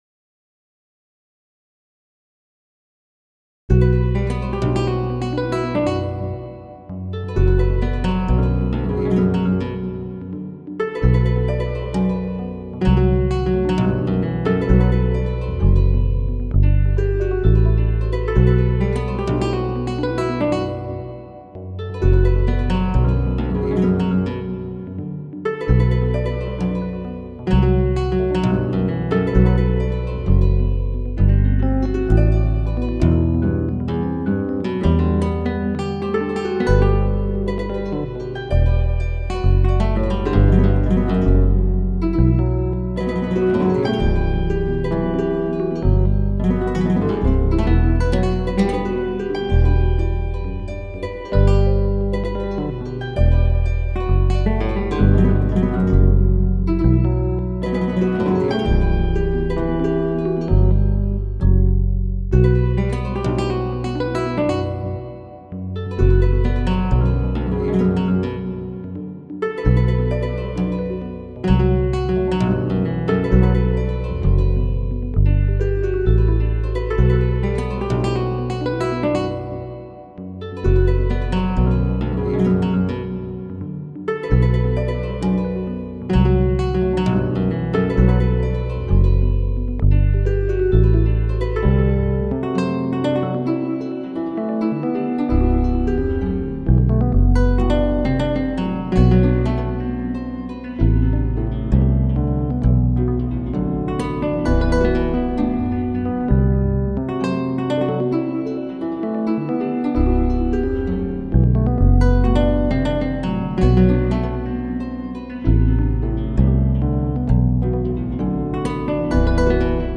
a labyrinth of classical spanish guitar